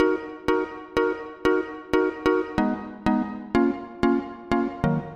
Sounds nice for a Chillout Summer Jam.
标签： 100 bpm Chill Out Loops Organ Loops 1.30 MB wav Key : Unknown
声道立体声